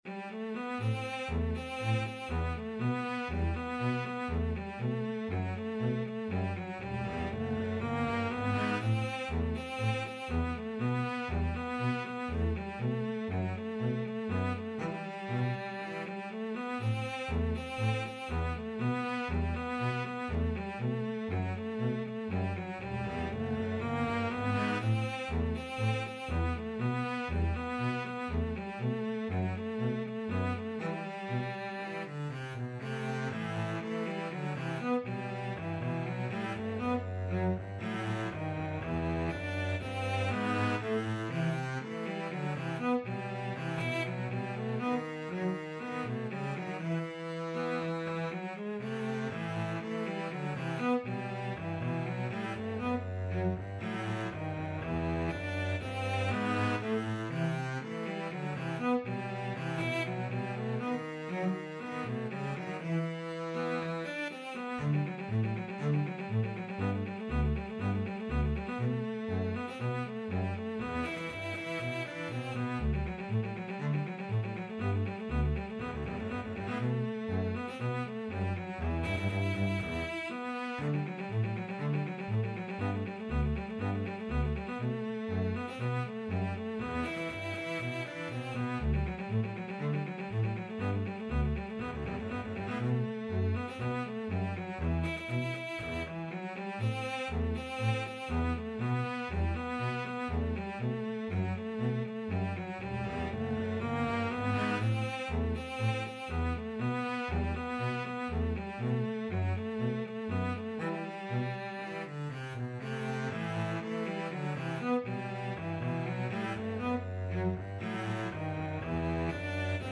Free Sheet music for Cello-Bass Duet
CelloDouble Bass
G major (Sounding Pitch) (View more G major Music for Cello-Bass Duet )
Fast Two in a Bar =c.120
2/2 (View more 2/2 Music)